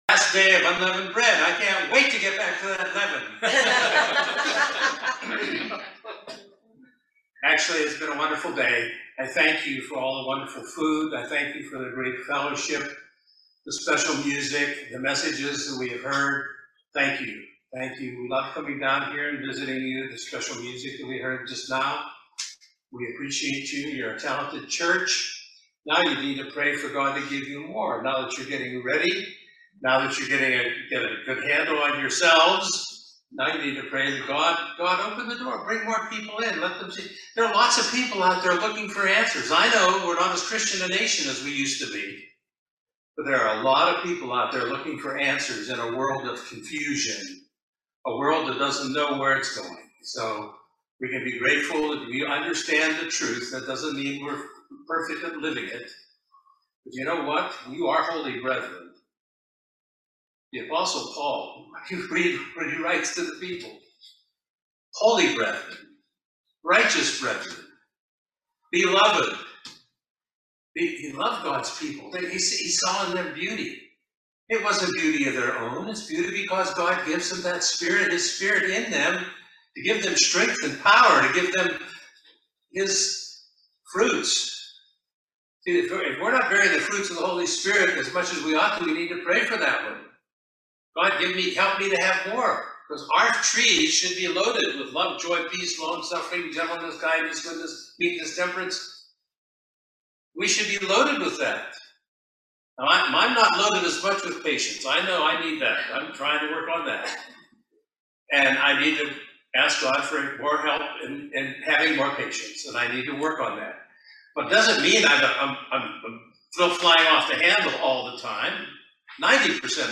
Do you know what the three tempters are? Join us for this excellent video Sermon that explains the three tempters, and shows us seven ways we can deal with the tempters.